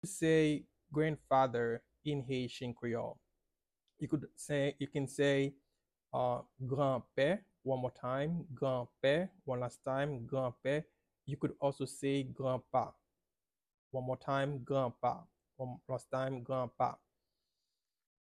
Listen to the correct pronunciation for Granpè and “Granpa”” in Haitian Creole (Audio Download)
2-ways-to-say-Grandfather-in-Haitian-Creole-with-pronunciation.mp3